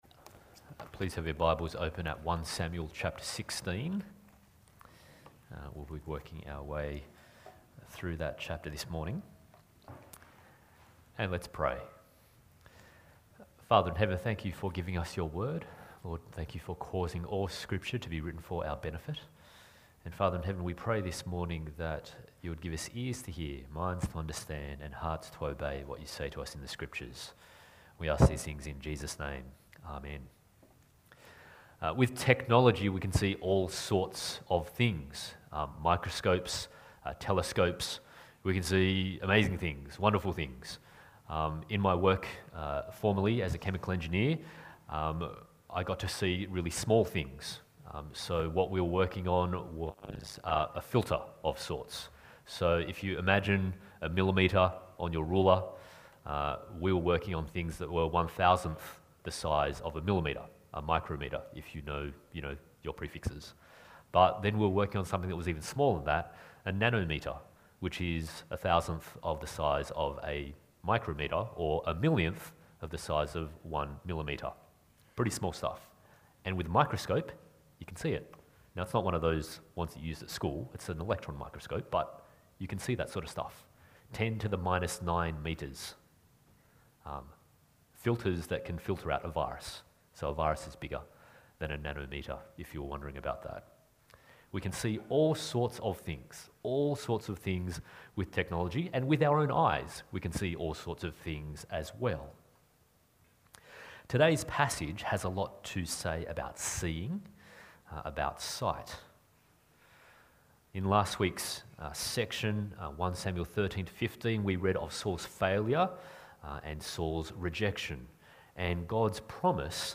1 Samuel Passage: 1 Samuel 16:1-23, Deuteronomy 7:1-8, Luke 9:28-36 Service Type: Sunday Morning